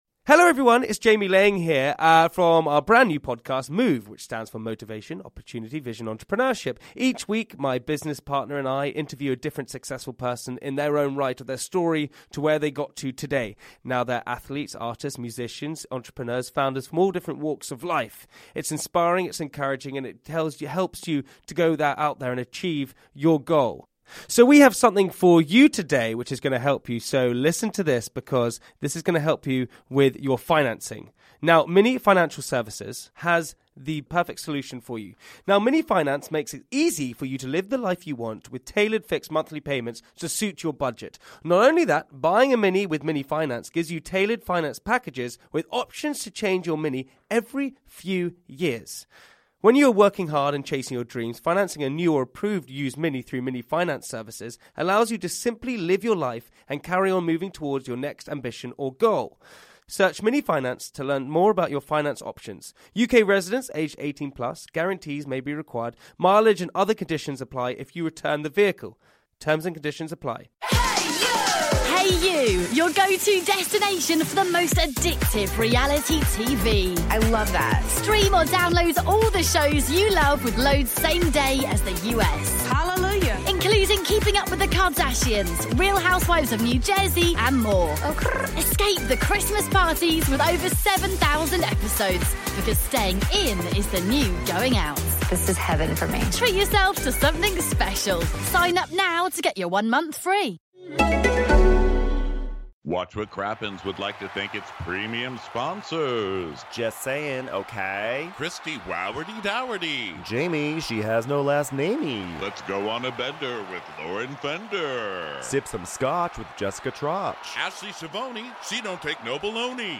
It's our last live show of 2020 and we're celebrating in Seattle with this touching Christmas episode. The Real Housewives of New Jersey end their trip to Jamaica with a Jennifer hosted roast, but Danielle manages to steal the show in twenty seconds of phone time.
Lots of bonus Christmas carols by special guests!